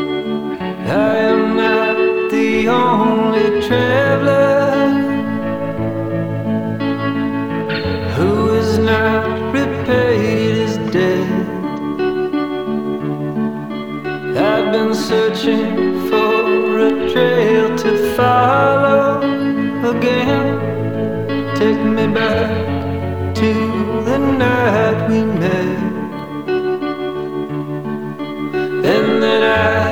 • Indie Rock